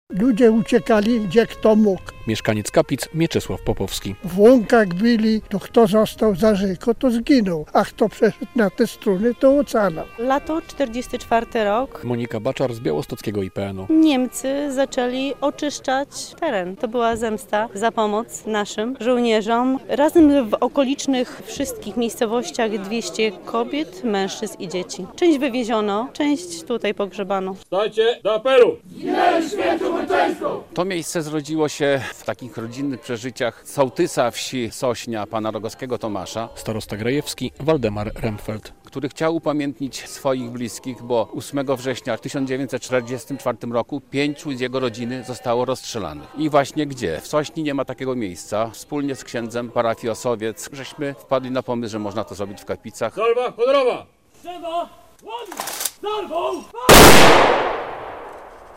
Pomnik w Kapicach przypomina tragedię mieszkańców zamordowanych przez Niemców - relacja